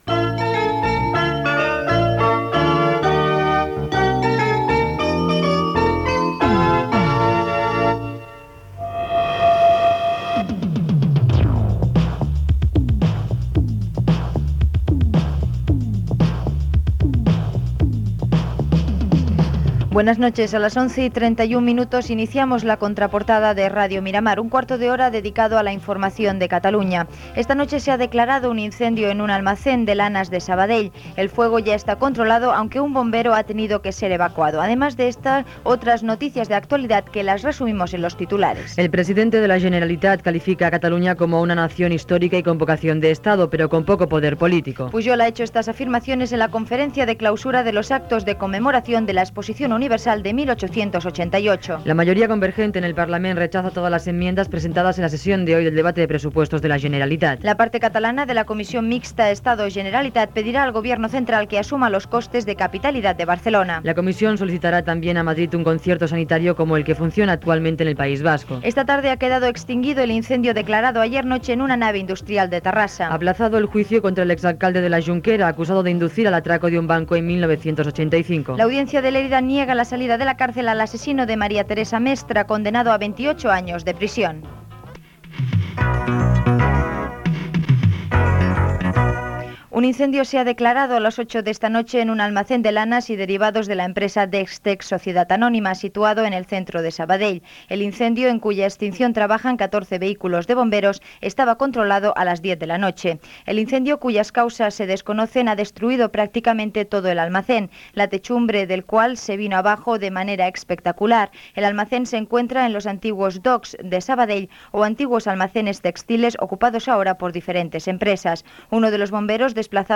Resum informatiu Gènere radiofònic Informatiu